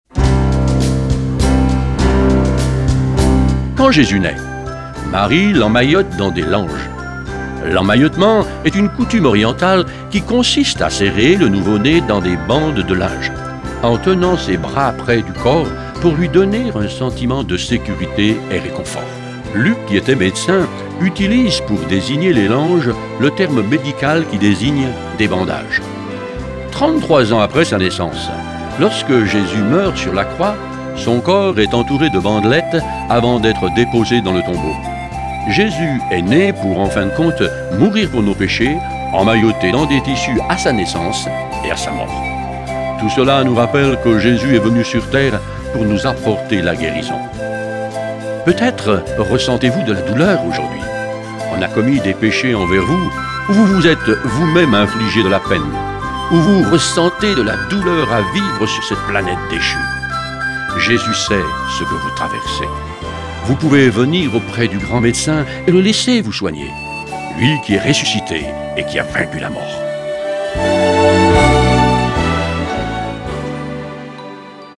Une série de méditations pour le mois de Décembre
Version audio Phare FM :